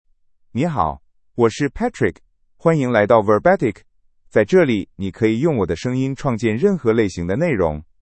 MaleChinese (Mandarin, Simplified)
PatrickMale Chinese AI voice
Voice sample
Male